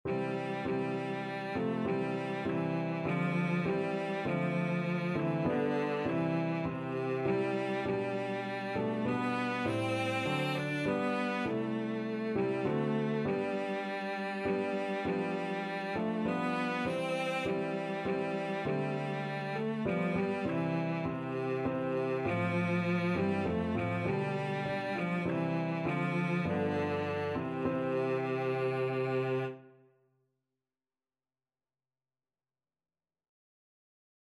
Christian Christian Cello Sheet Music We Gather Together
Cello
3/4 (View more 3/4 Music)
C major (Sounding Pitch) (View more C major Music for Cello )
Traditional (View more Traditional Cello Music)
we_gather_together_VLC.mp3